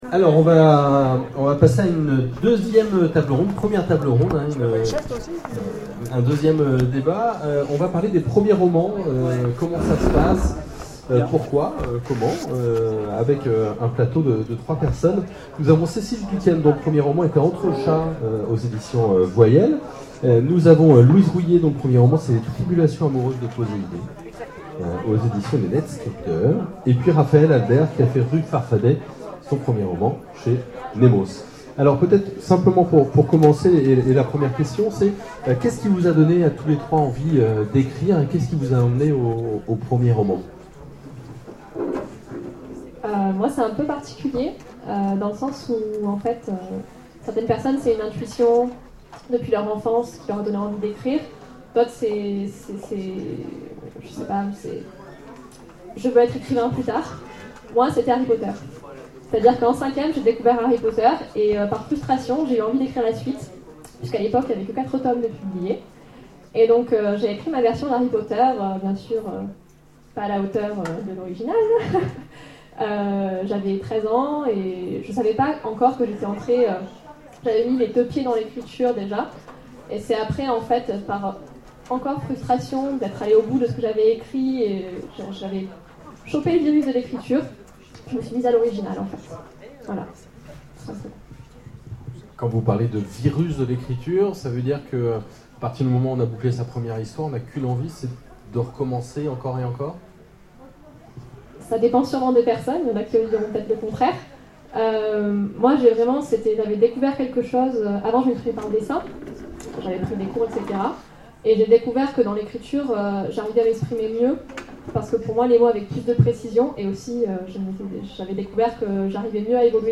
Festival Autres Mondes : Rencontre avec de jeunes auteurs pour leurs premiers romans
Conférence